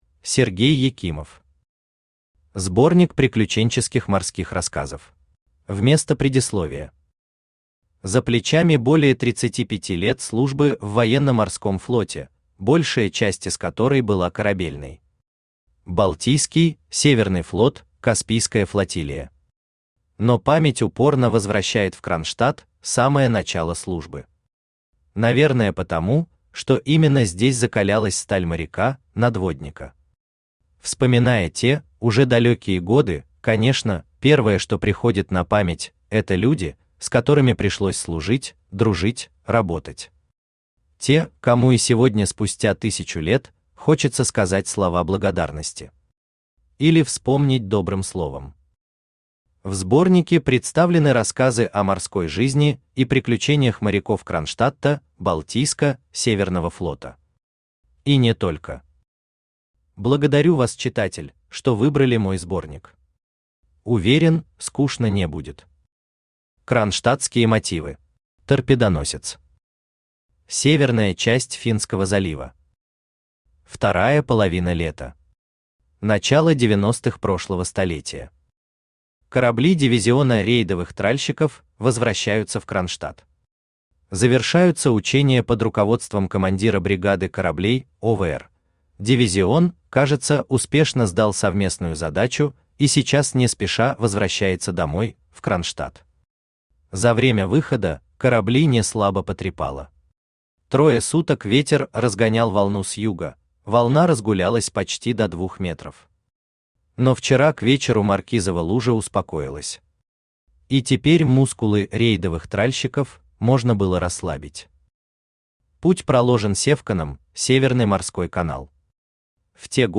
Аудиокнига Сборник приключенческих морских рассказов | Библиотека аудиокниг
Aудиокнига Сборник приключенческих морских рассказов Автор Сергей Петрович Екимов Читает аудиокнигу Авточтец ЛитРес.